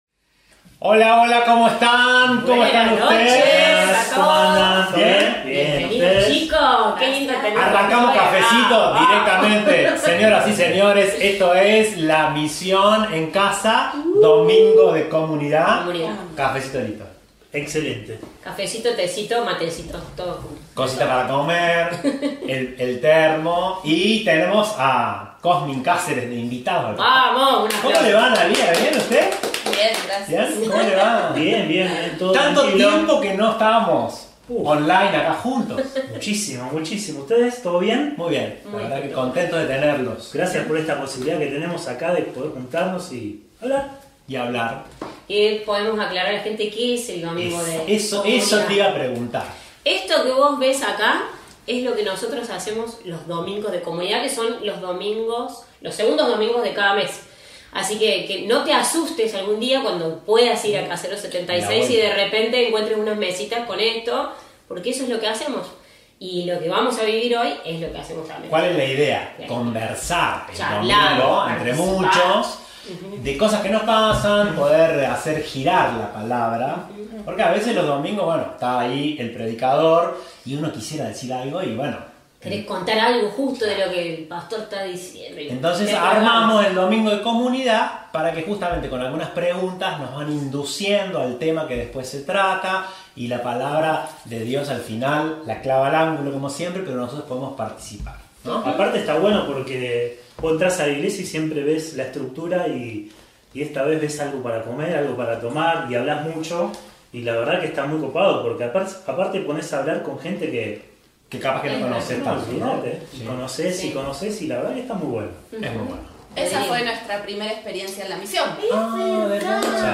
REUNIÓN DE COMUNIDAD – CAFECITO ¡Bienvenidos una vez más a nuestras reuniones online! Los segundos Domingos del mes tenemos nuestra reunión de comunidad, con cafecito, cosas ricas y donde compartimos preguntas y respuestas escuchándonos atentamente.